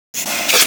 c_viper_atk3.wav